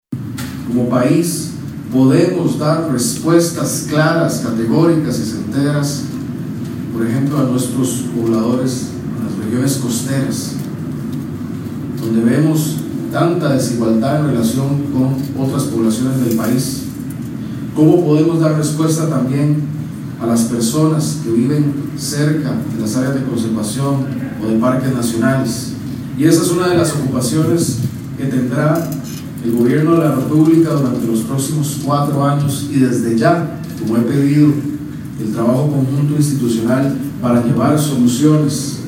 Por su parte el mandatario Alvarado expresó que el Gobierno tiene un compromiso de llevar soluciones a las comunidades cercanas a zonas que son de conservación.